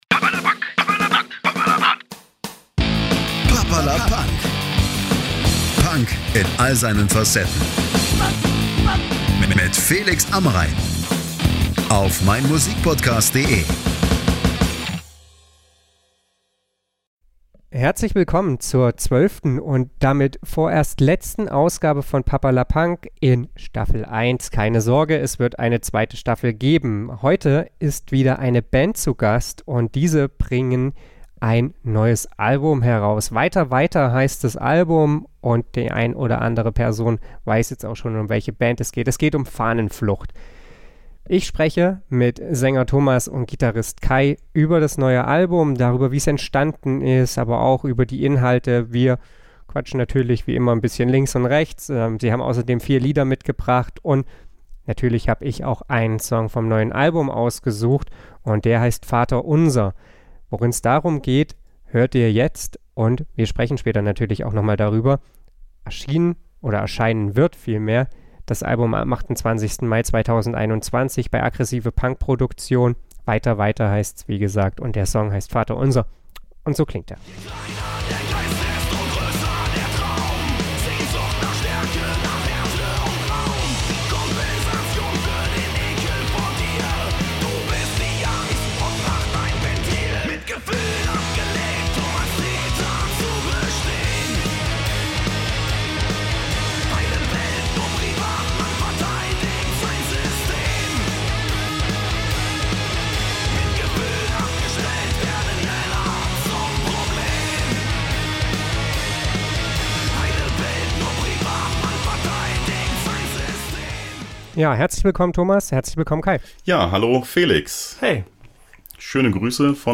Den Podcast haben wir am 14.05.2021 via StudioLink aufgenommen.